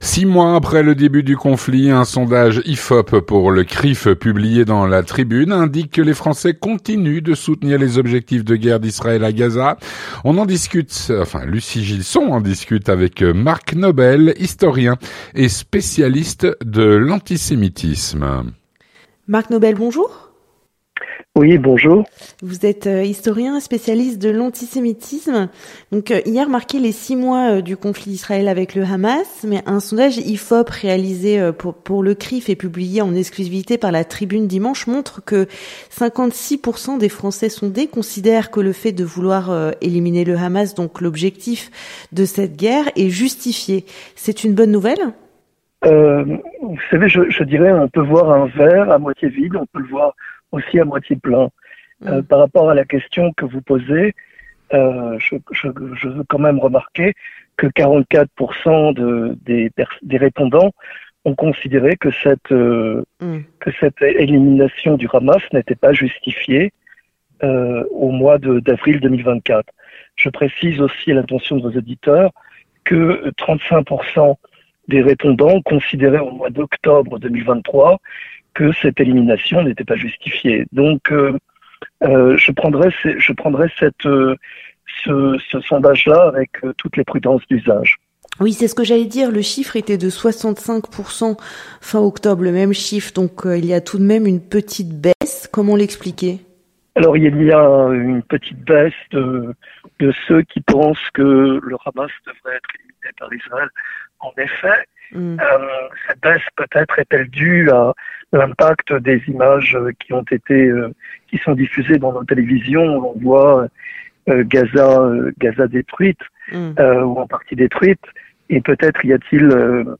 L'entretien du 18H - un sondage IFOP pour le CRIF indique que les Français continuent de soutenir les objectifs de guerre d'Israël à Gaza.